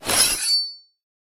knife_draw.ogg